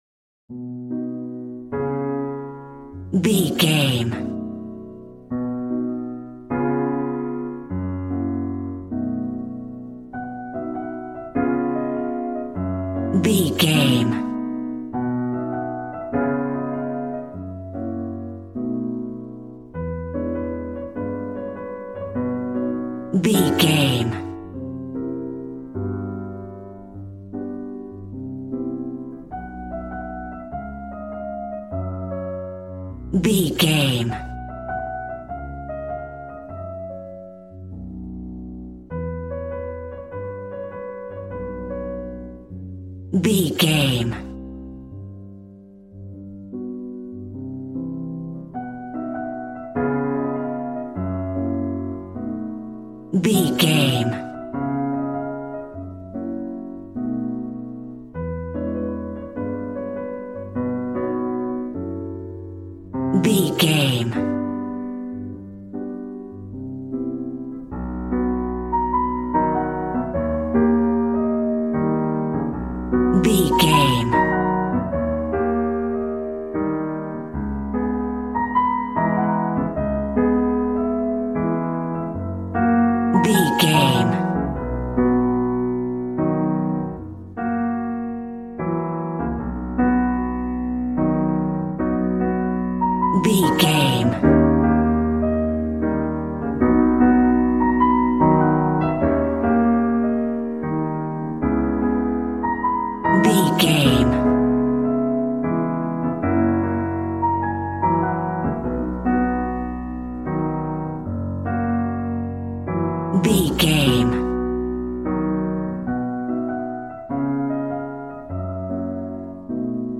Smooth jazz piano mixed with jazz bass and cool jazz drums.,
Ionian/Major